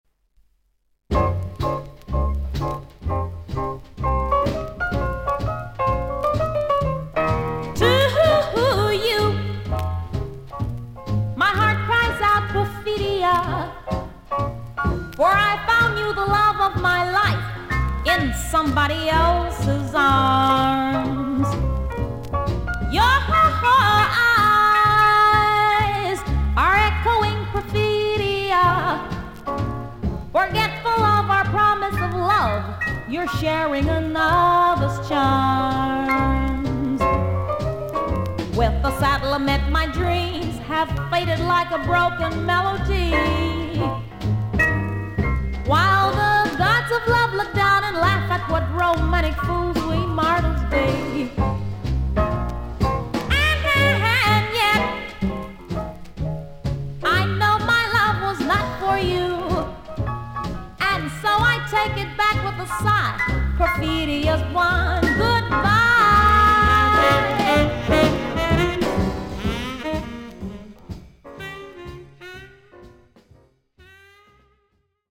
伸びやかな声の本格派ジャズ・シンガー。
VG++〜VG+ 少々軽いパチノイズの箇所あり。クリアな音です。